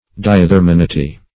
Search Result for " diathermaneity" : The Collaborative International Dictionary of English v.0.48: Diathermancy \Di`a*ther"man*cy\, Diathermaneity \Di`a*ther`ma*ne"i*ty\, n. [See Diathermanous .] The property of transmitting radiant heat; the quality of being diathermous.
diathermaneity.mp3